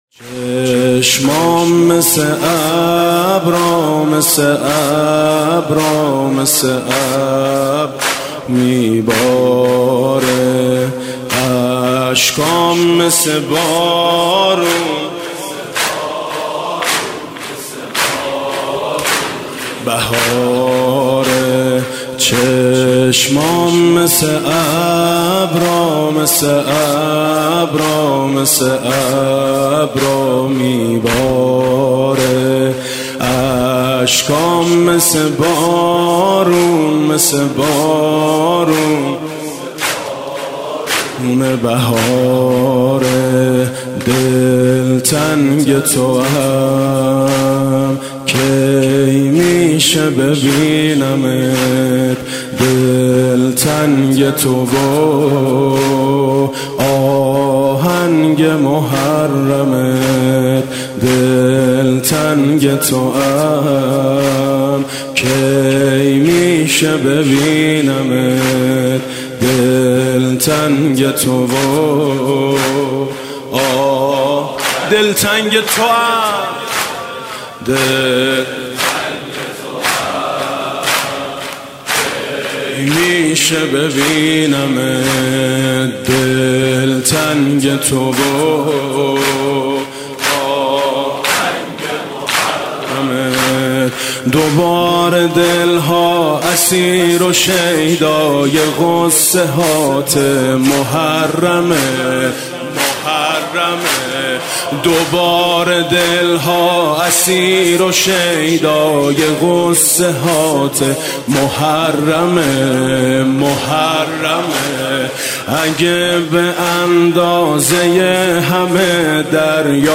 فایل صوتی گلچین مداحی میثم مطیعی ویژه محرم ۹۷ در این گزارش قابل دریافت است.
تهران- الکوثر: به مناسبت ماه محرم و ایام عزاداری سالار شهیدان گلچینی‌ از مداحی میثم‌ مطیعی در شب اول محرم قابل دریافت است.